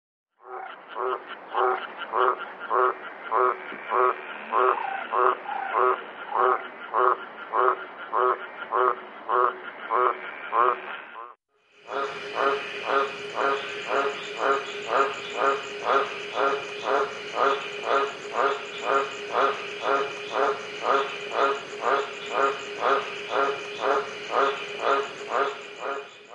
25. Common Green Tree Frog
What is special about me? I make a sound like “crawk crawk”
GreenTreeFrog.mp3